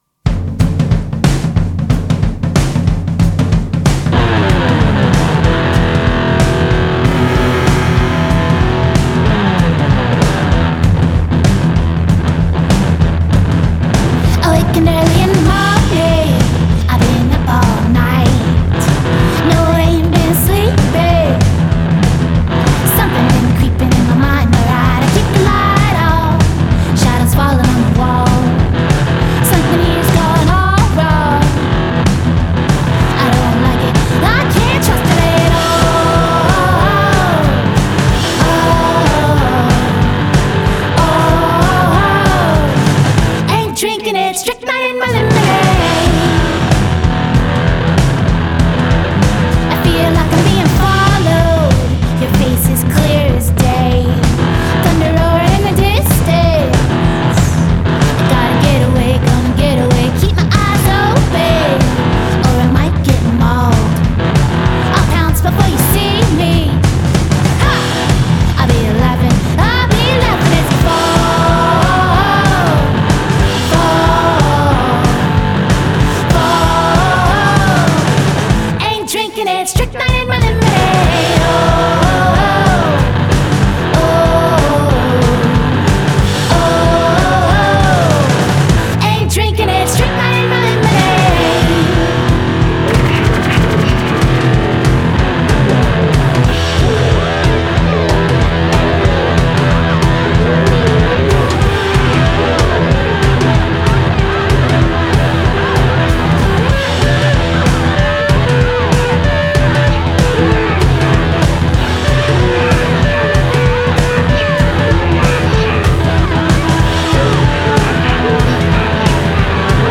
FACEBOOK Rock isn't dead, it's just festering.